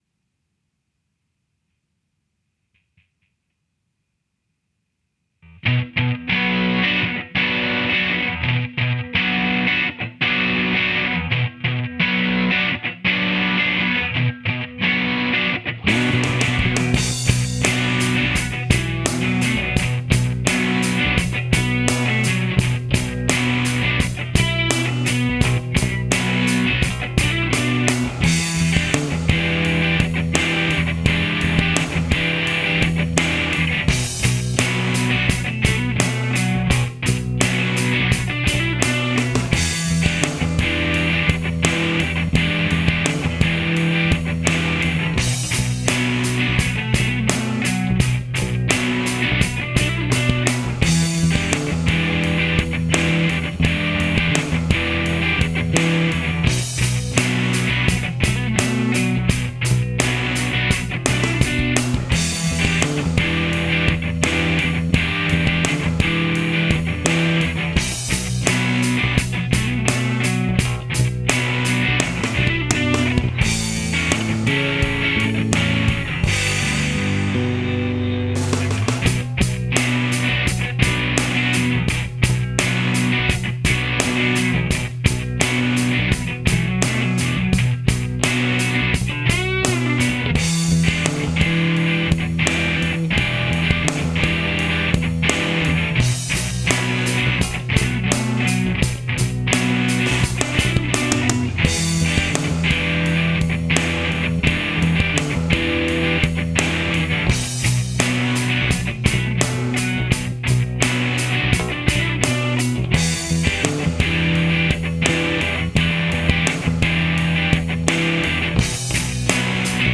Rocksong ohne Gesang